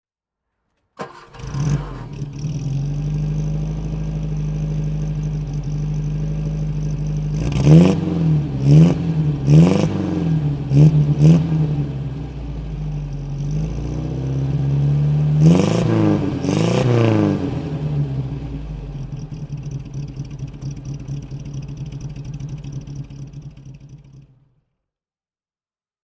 Ford Anglia De Luxe (1961) - Starten und Leerlauf
Ford_Anglia_1961.mp3